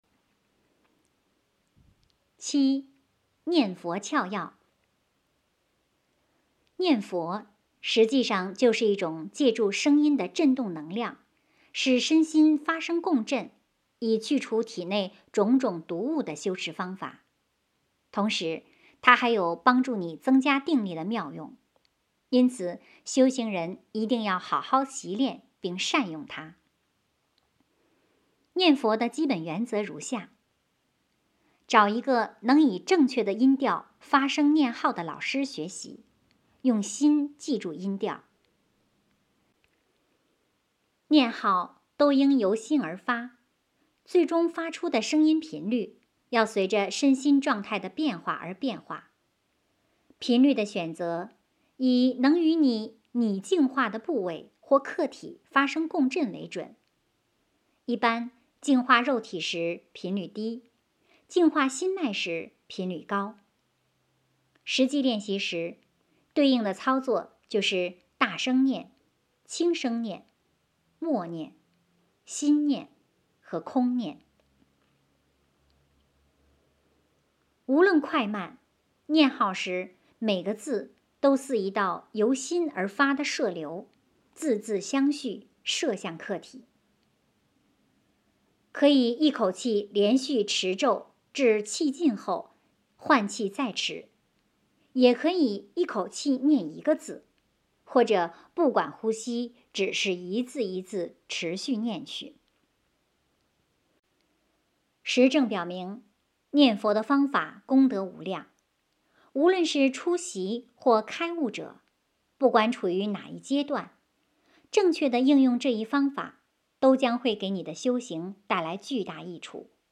有声读物 - 实修世界